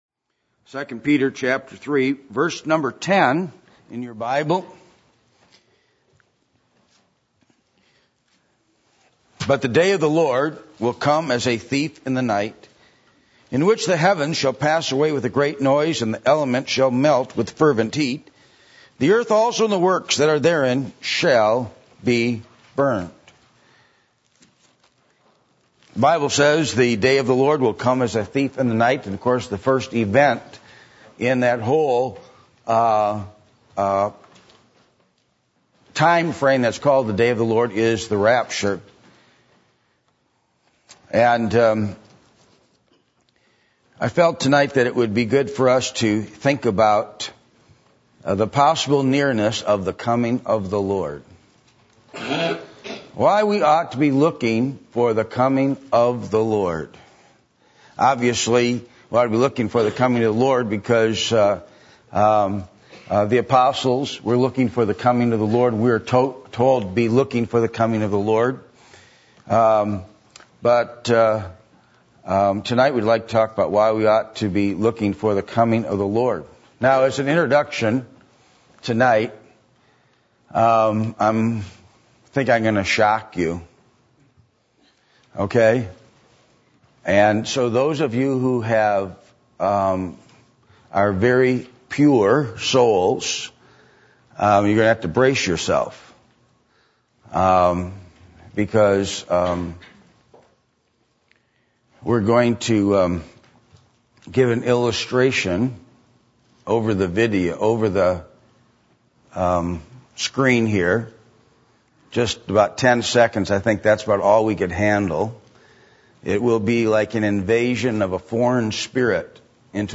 Passage: 2 Peter 3:10-12 Service Type: Sunday Evening %todo_render% « Six Truths About Death Whose Will Are You Seeking After?